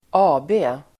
Ladda ner uttalet
AB förkortning (i firmanamn), Ltd. , Co. , Inc. Uttal: [²'a:be:] Definition: aktiebolag(et) (limited company, joint-stock company) Exempel: Svenska Cellulosa AB (Swedish Cellulosa Ltd.), AB Svensk Bilprovning (The Swedish Motor Vehicle Inspection Co.)